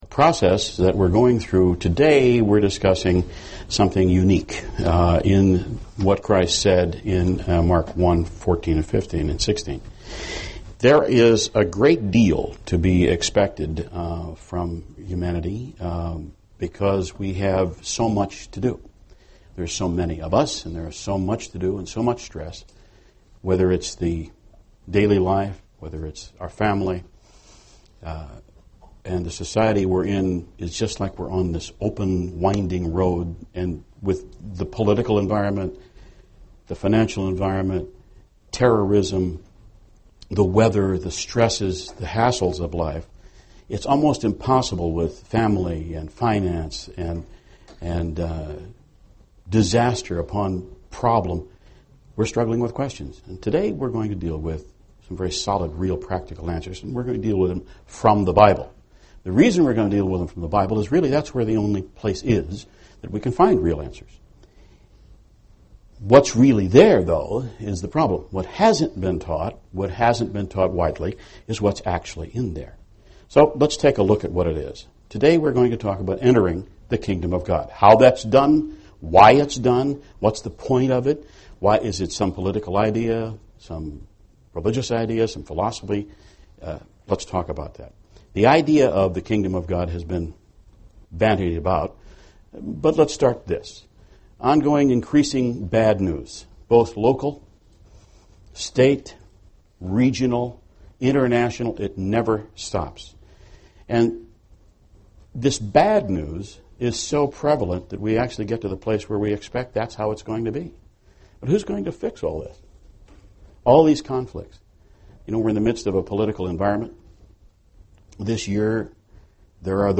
Where can you find real, practical answers? This message was given for a Kingdom of God seminar.
UCG Sermon Studying the bible?